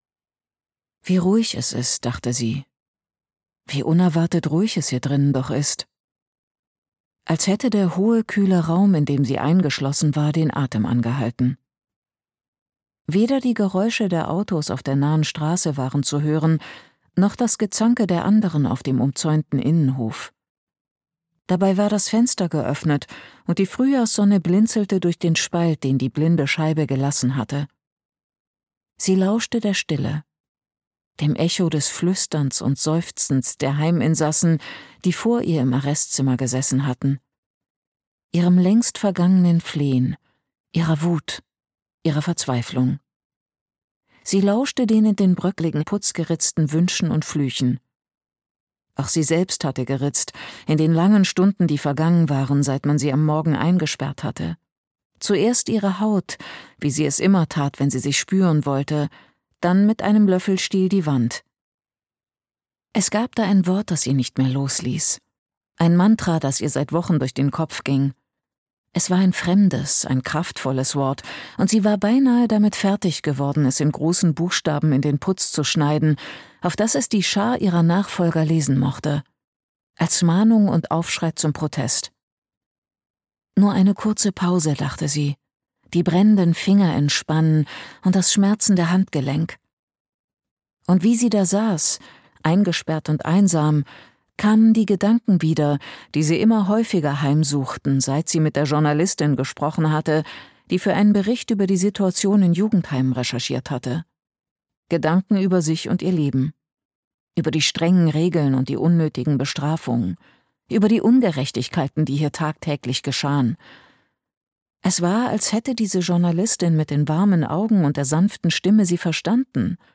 Rotwild - Roman Voosen, Kerstin Signe Danielsson | argon hörbuch
Gekürzt Autorisierte, d.h. von Autor:innen und / oder Verlagen freigegebene, bearbeitete Fassung.